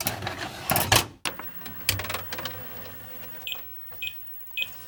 weaponSafe1.wav